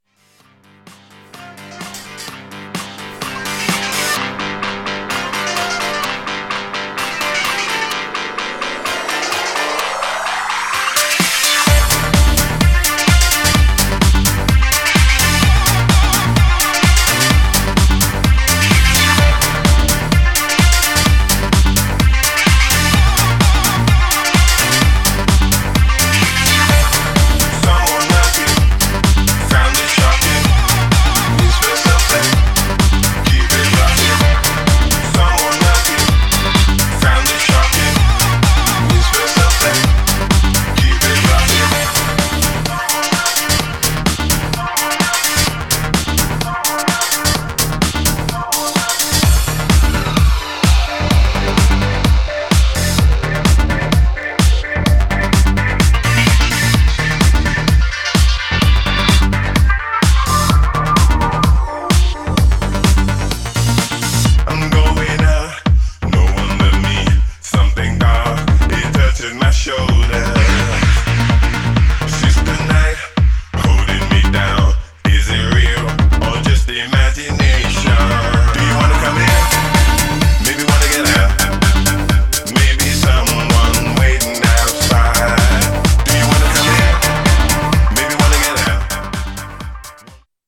Styl: Progressive, House